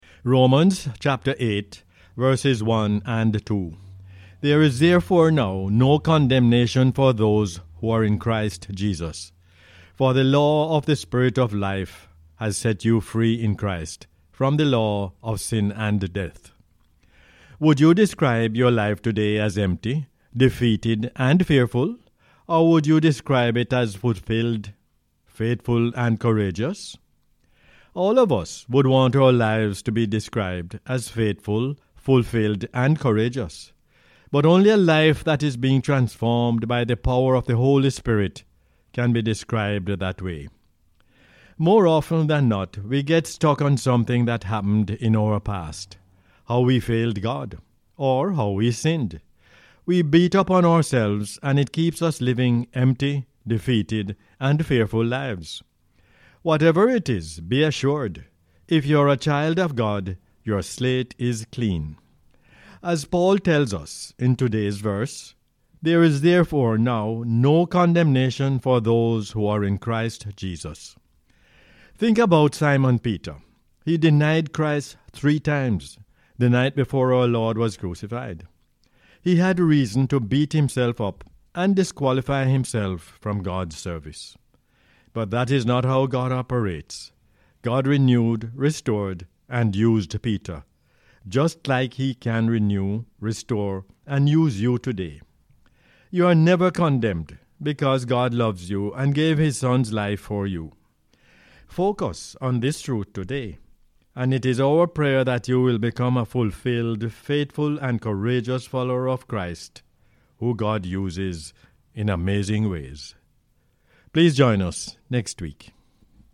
Romans 8:1-2 is the "Word For Jamaica" as aired on the radio on 14 October 2022.